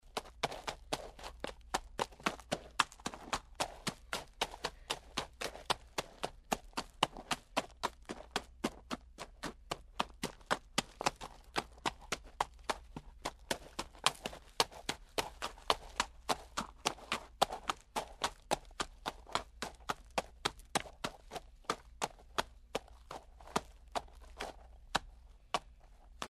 Лошадь шагает по асфальту